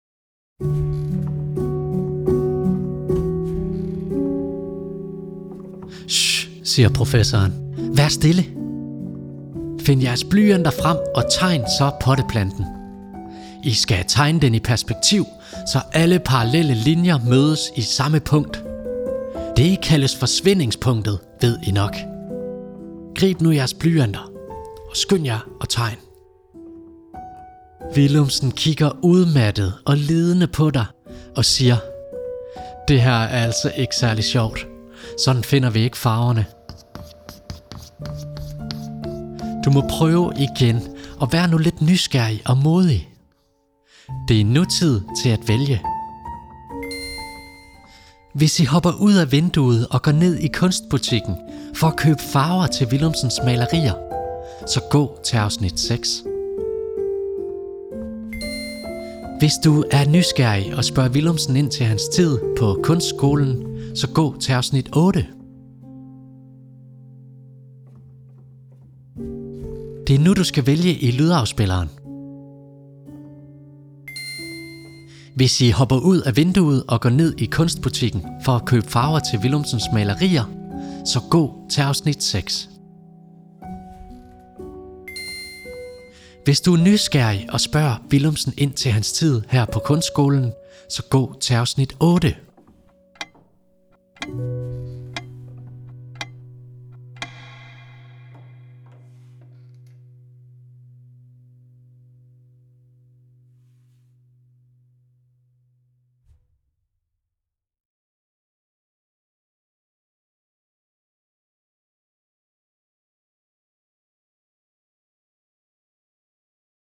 I den her lydfortælling skal du på eventyr med Willumsen.